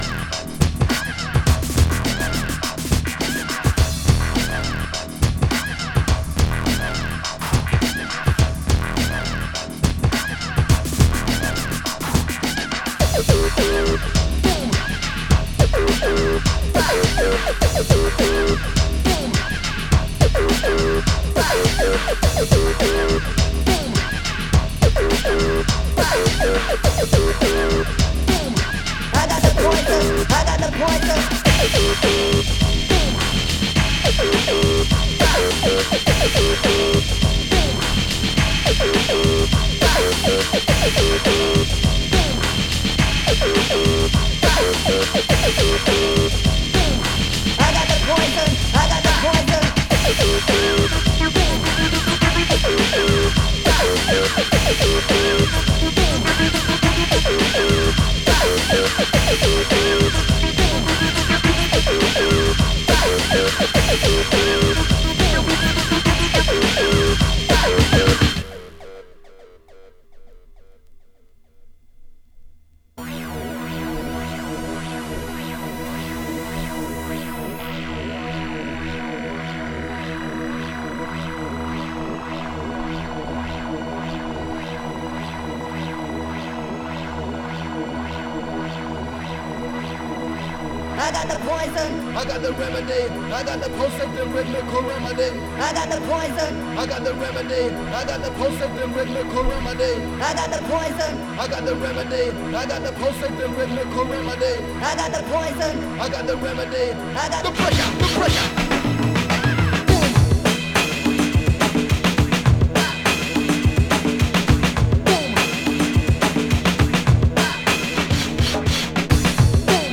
Styl: Drum'n'bass, Techno, Breaks/Breakbeat